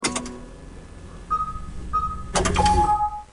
Home gmod sound vehicles tdmcars nis_leaf
enginestart.mp3